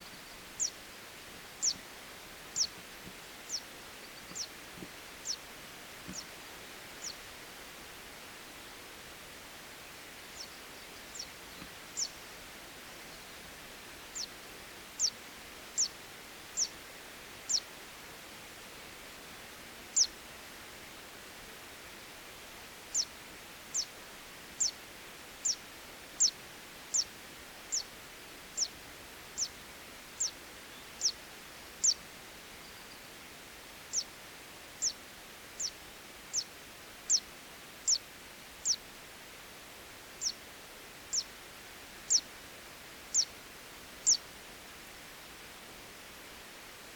Zistensänger Cisticola juncidis Zitting Cisticola
Loisach-Kochelsee-Moore
04.07.2007 48 s Gesang